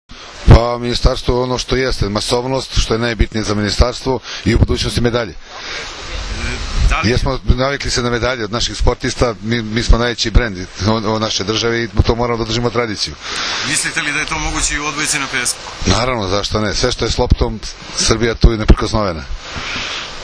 U beogradskom restoranu „Dijagonala” danas je svečano najavljen VI „Vip Beach Masters 2013.“ – Prvenstvo Srbije u odbojci na pesku, kao i Vip Beach Volley liga, u prisustvu uglednih gostiju, predstavnika gradova domaćina, odbojkašica, odbojkaša i predstavnika medija.
IZJAVA PREDRAGA PERUNIČIĆA, DRŽAVNOG SEKRETARA ZA SPORT